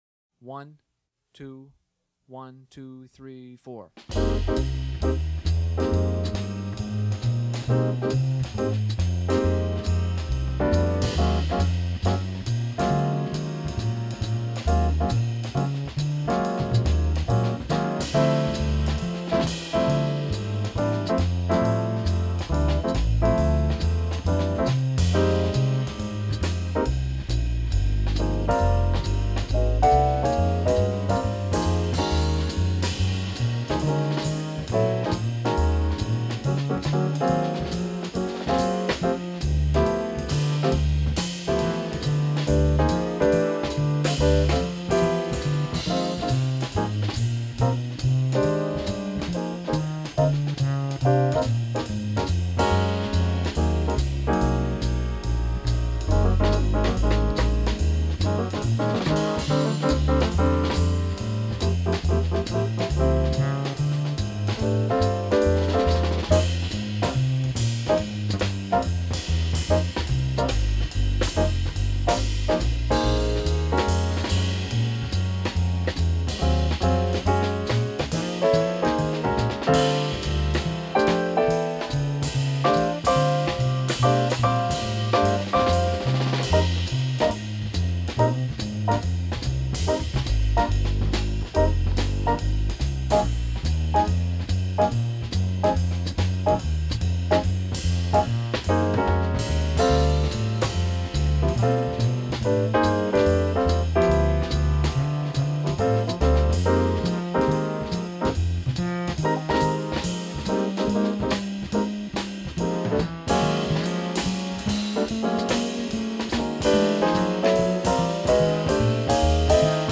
Sprechen
Mr. und Mrs. Smith - Synchronausschnitt